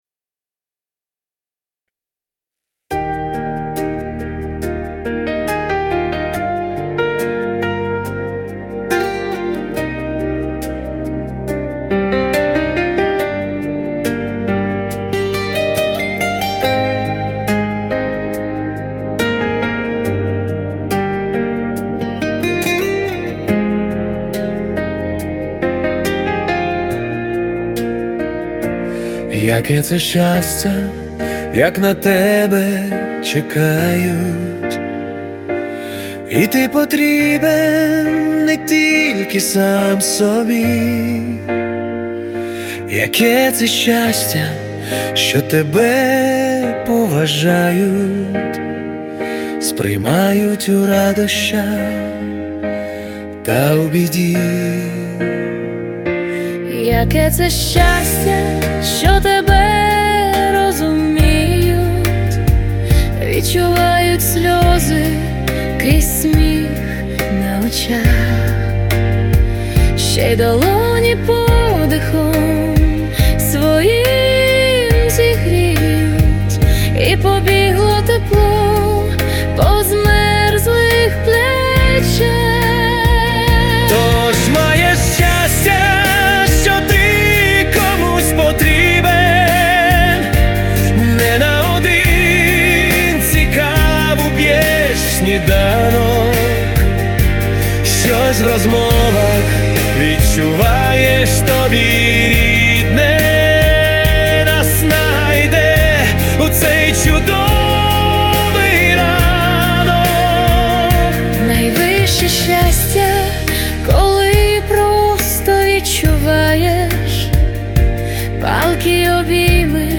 🎵 Жанр: Акустична балада
Ця прониклива акустична балада